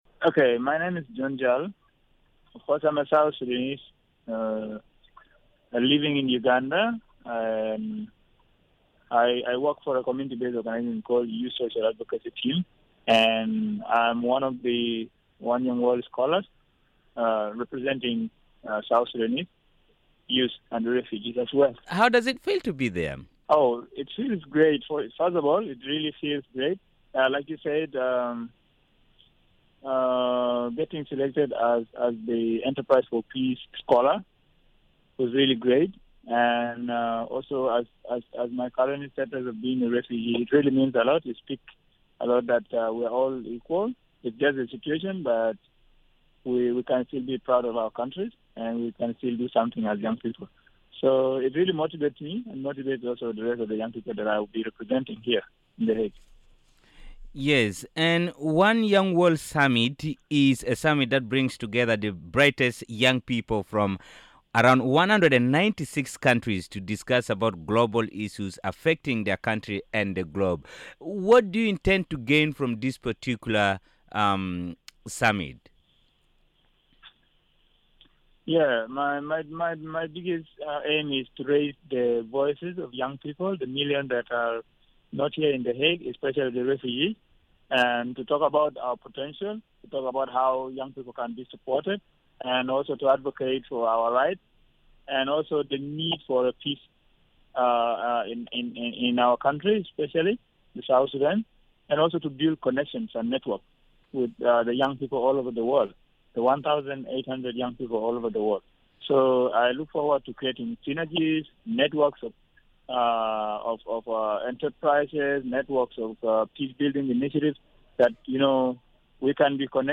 Here is the full interview as aired on Miraya Breakfast Show